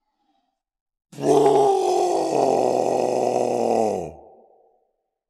horror
Dinosaur Roar 3